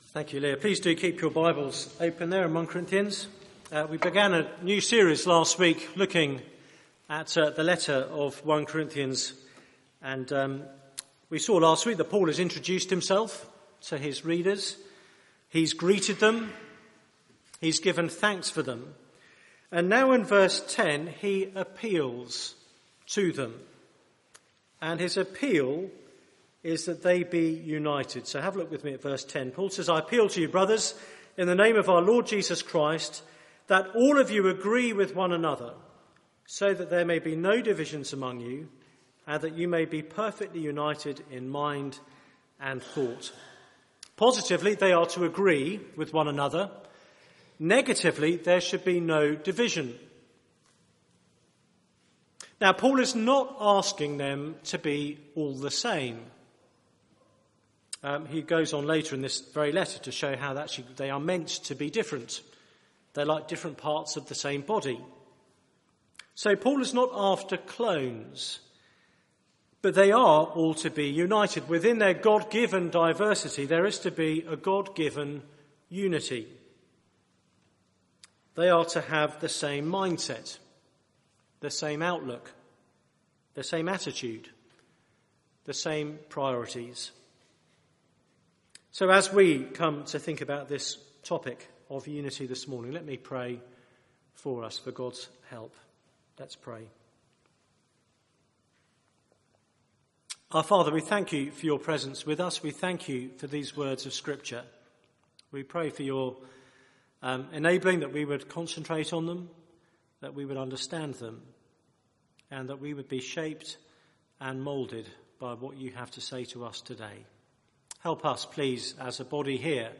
Media for 9:15am Service on Sun 26th Oct 2014 09:15 Speaker
A CHURCH WITH ISSUES Theme: A divided church Sermon